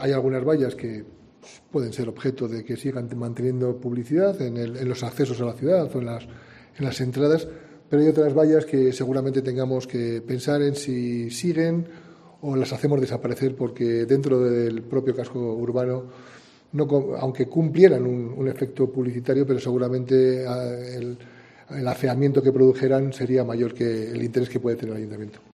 José Mazarías, alcalde de Segovia